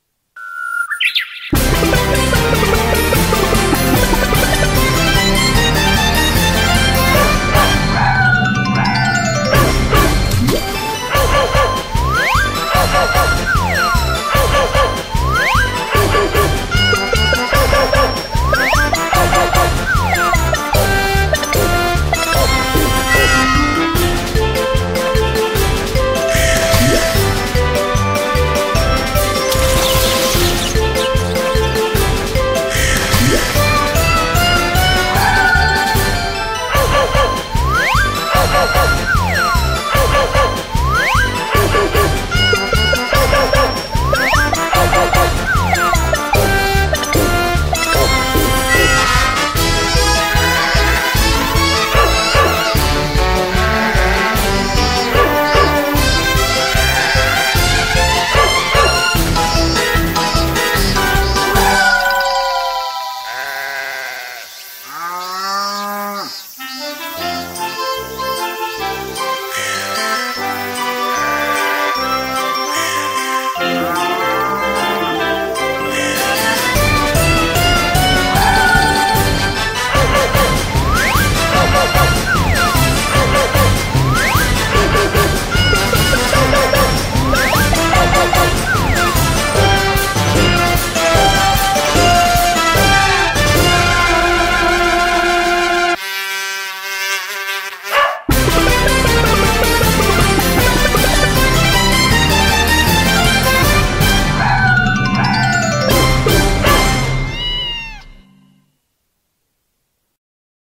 BPM150-158
Audio QualityPerfect (Low Quality)